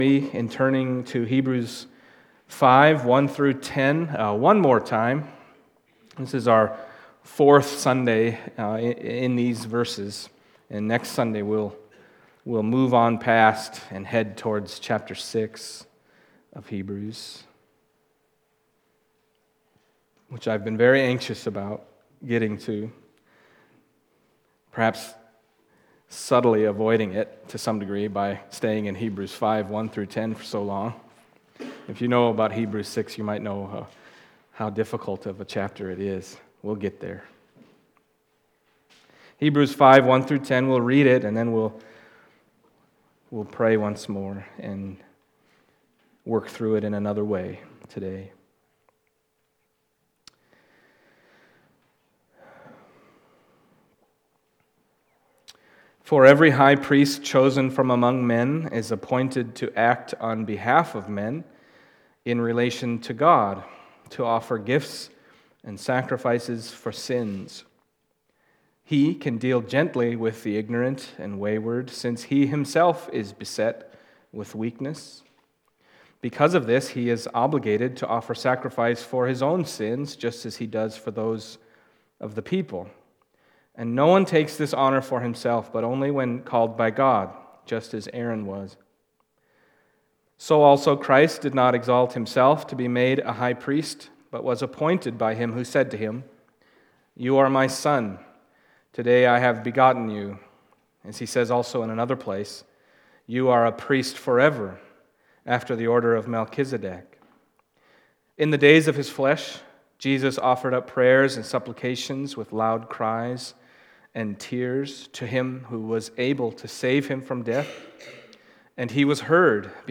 Hebrews Passage: Hebrews 5:1-10 Service Type: Sunday Morning Hebrews 5:1-10 « Christ the Substitute Have You Become Dull of Hearing?